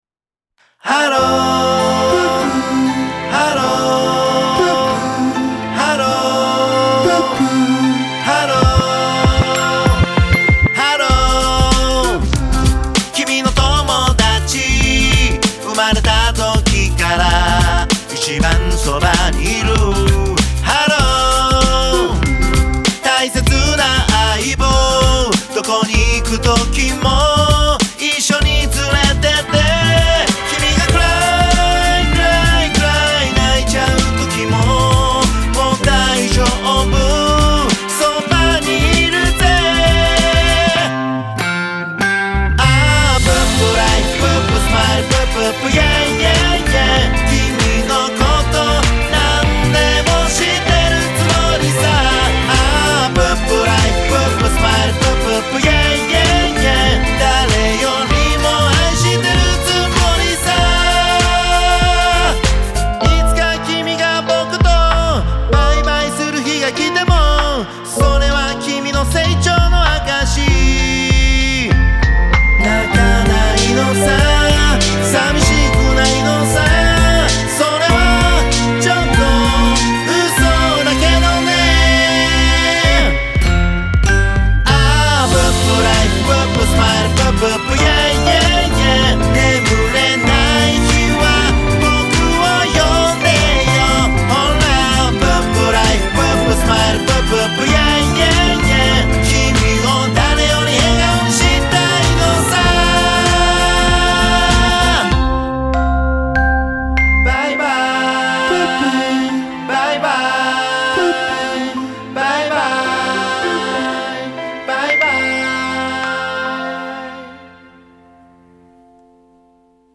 ROCK / POPS